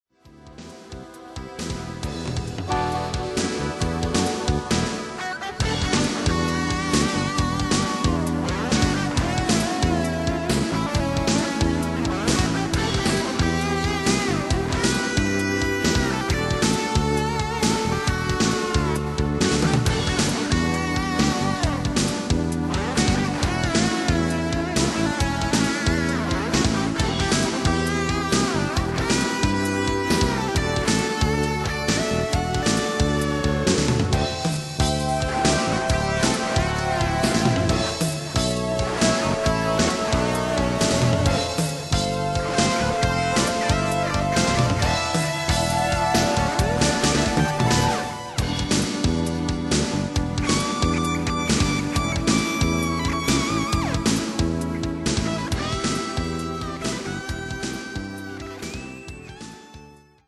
今日できた曲はちょいフュージョンよりやなあ。
曲は「フォース・ディメンジョン」というタイトルです。それにしても懐かしいU-220の音ですなあ(^o^;)。